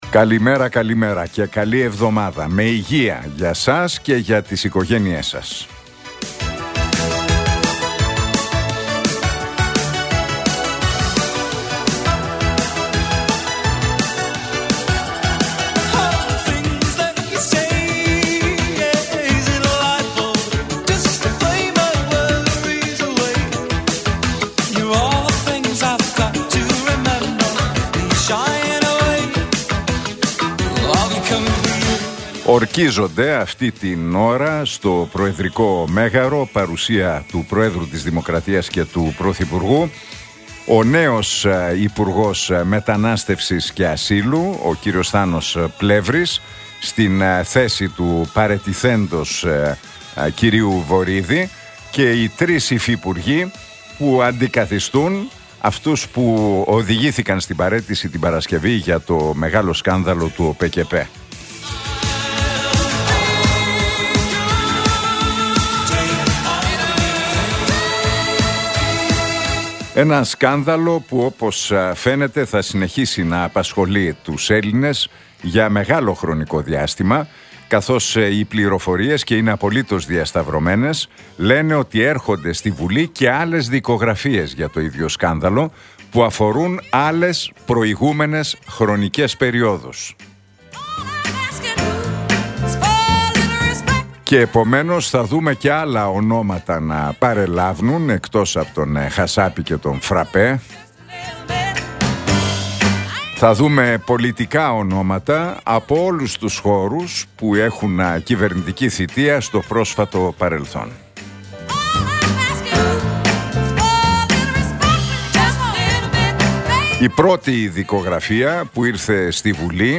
Ακούστε το σχόλιο του Νίκου Χατζηνικολάου στον ραδιοφωνικό σταθμό Realfm 97,8, την Δευτέρα 30 Ιουνίου 2025.